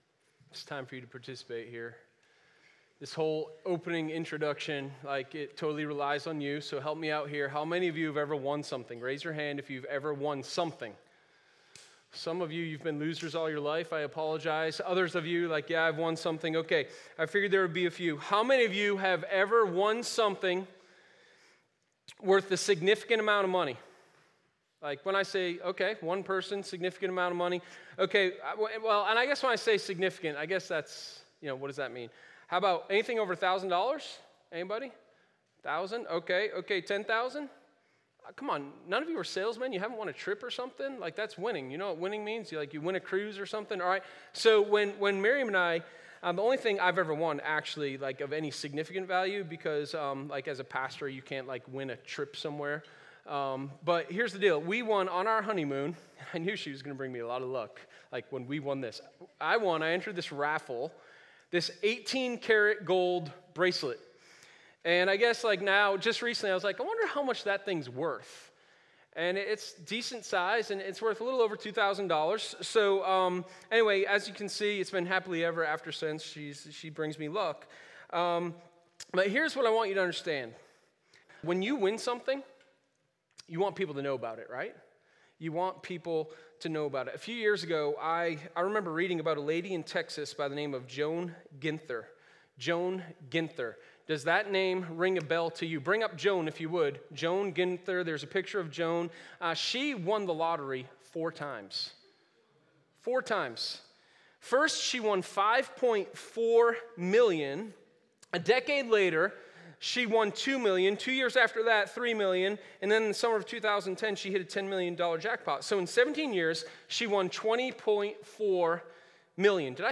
Sermon0514_3RenewyourMissionPt.1.mp3